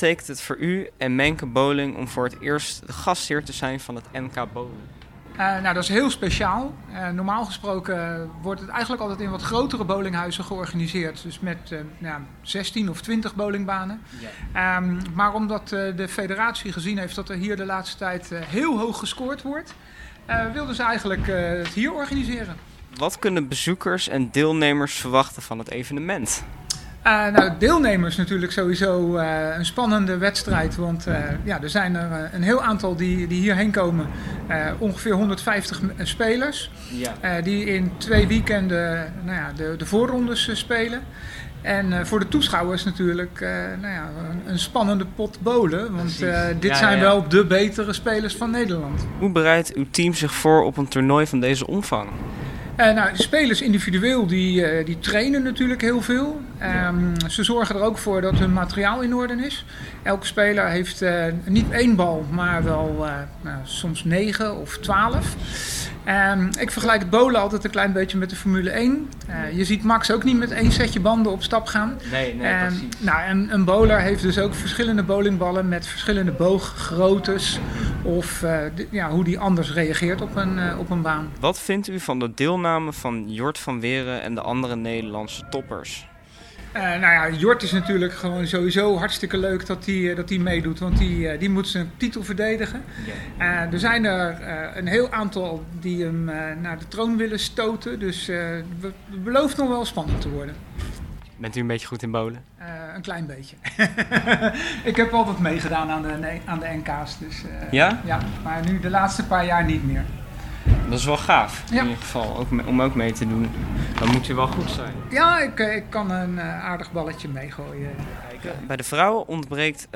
Interview Leiden Sport